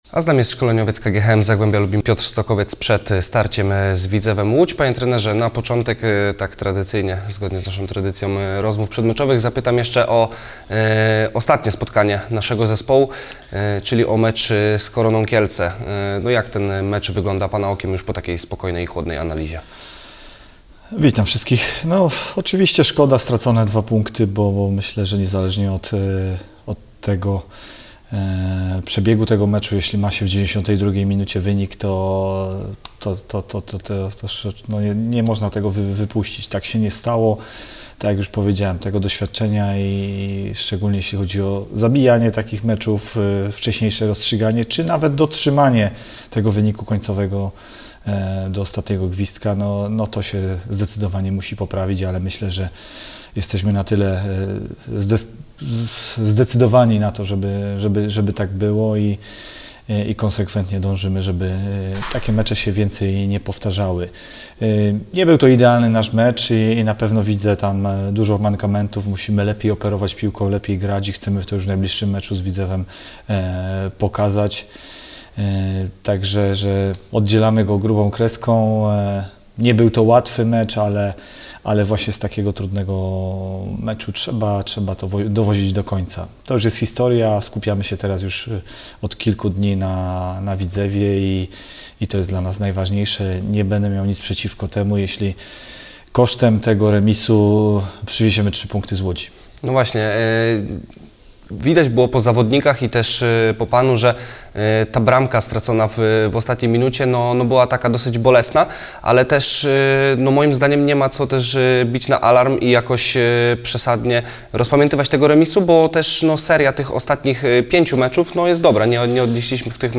Przed wyjazdowym meczem KGHM Zagłębia z Widzewem Łódź, porozmawialiśmy z trenerem Piotrem Stokowcem.